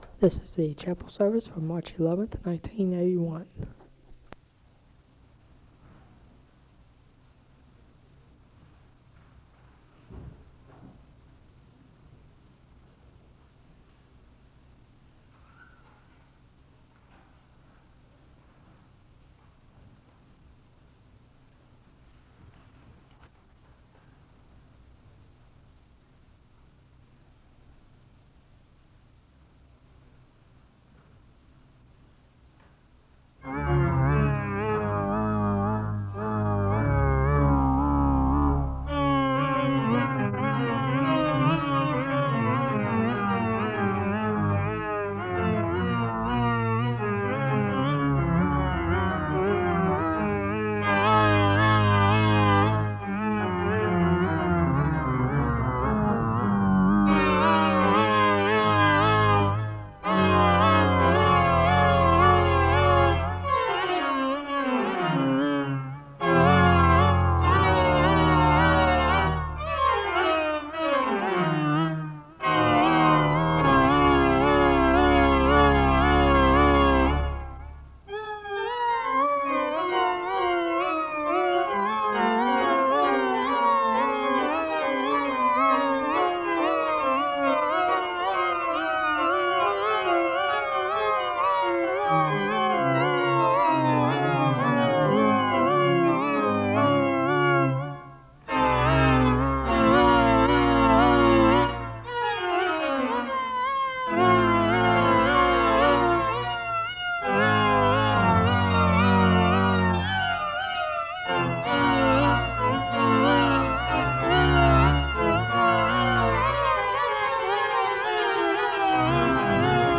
The service begins with organ music (00:00-06:50).
The choir sings a song of worship...
Lenten sermons
Location Wake Forest (N.C.)